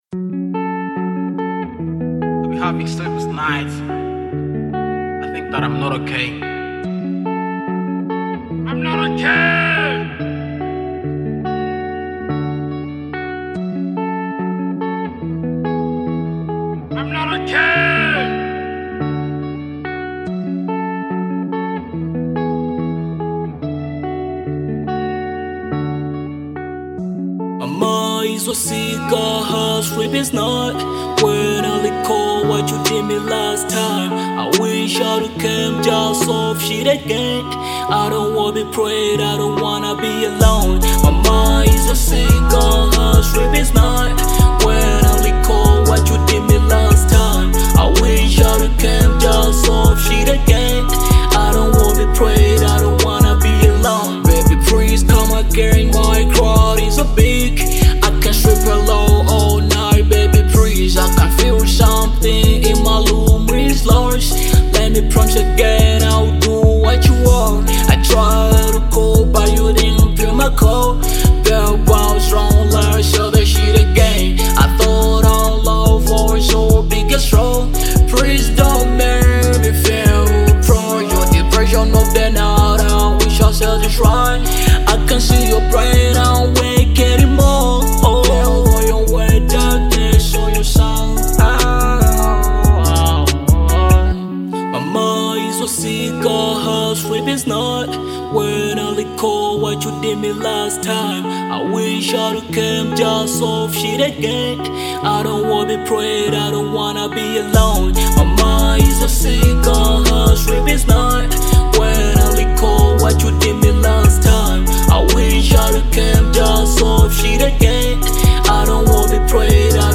Genre : Hiphop/Trap